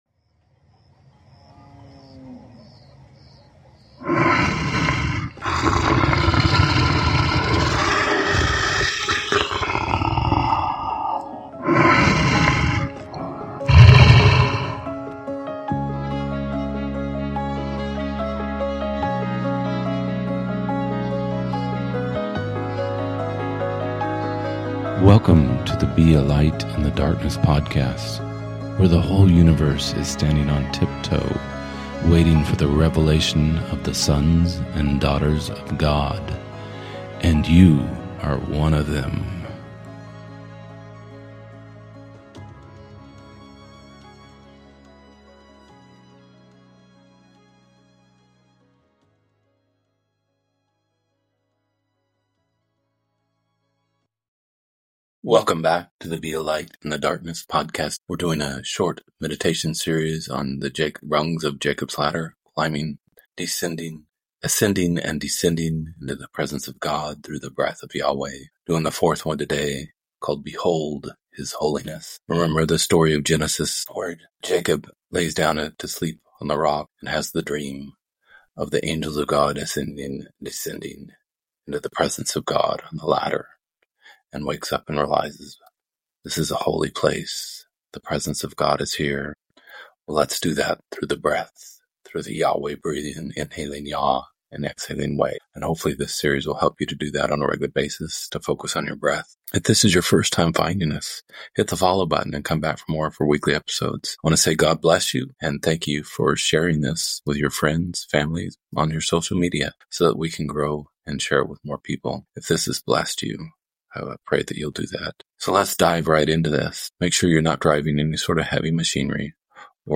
00:00 Introduction to Meditation and Breath